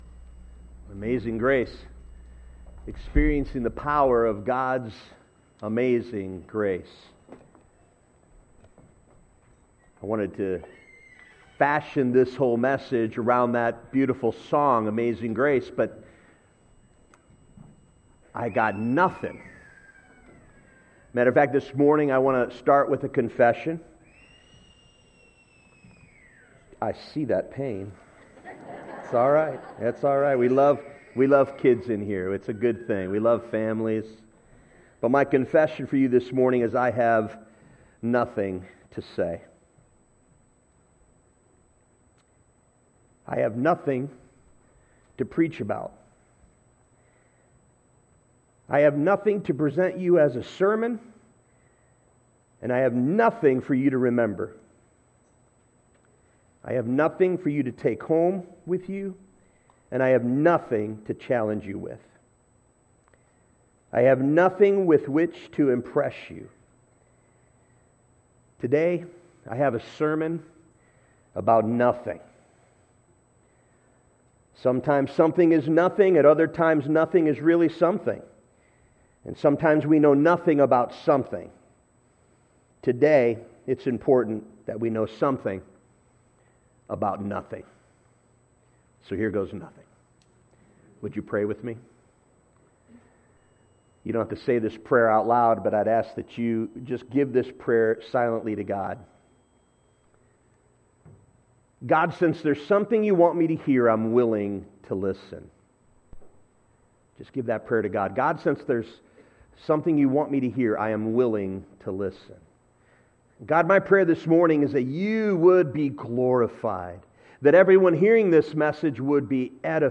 Oakwood Community Church Message Podcast | Oakwood Community Church